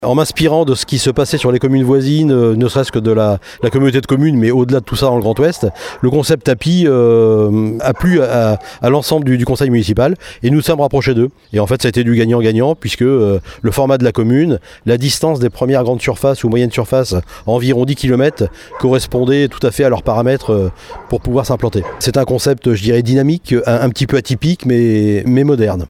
La mairie a investi 4 000 euros pour son implantation et offrir un véritable service de proximité, comme le souligne le maire Christophe Rault :